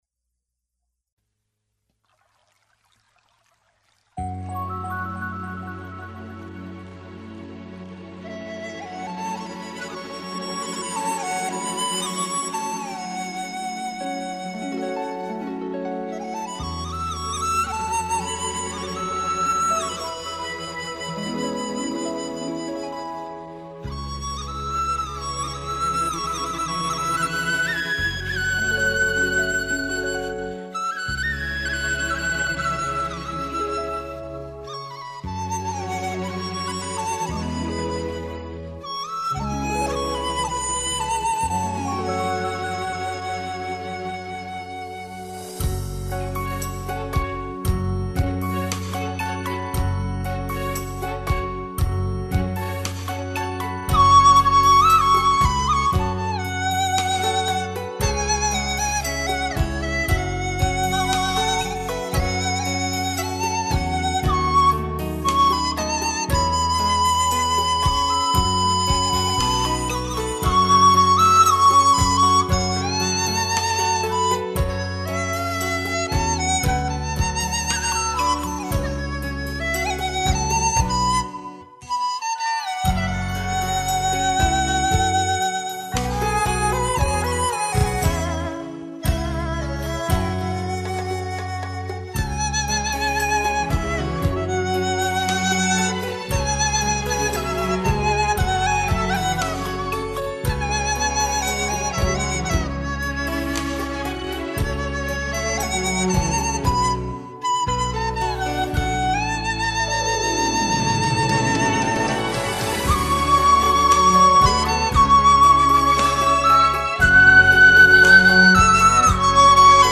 这首歌描述恋人在风雨桥相思念的情景，有南方民歌风味。
愉悦的旋律，清脆动人的笛声令人流连忘返！
悠扬的笛声，夏天听来格外清心舒爽的感觉：）